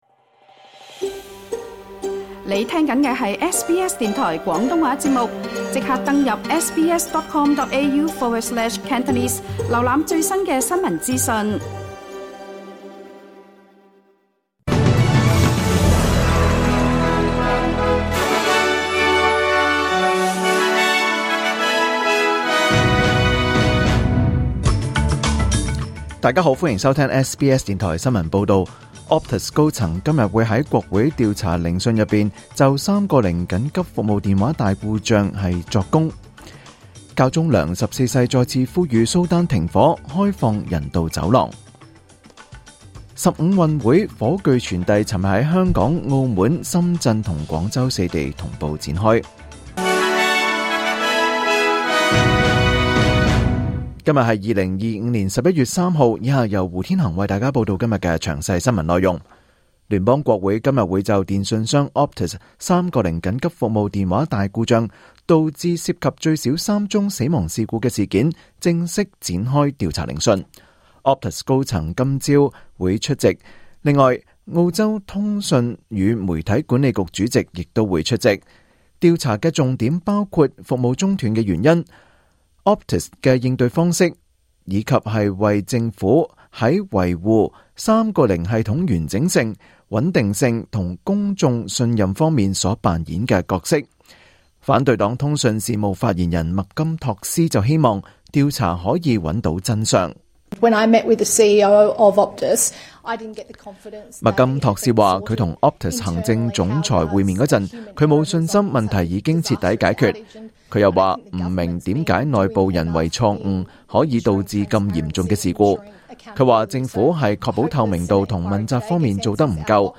2025 年 11 月 3 日 SBS 廣東話節目詳盡早晨新聞報道。